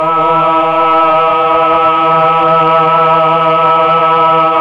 Index of /90_sSampleCDs/Roland LCDP09 Keys of the 60s and 70s 1/VOX_Melotron Vox/VOX_Tron Choir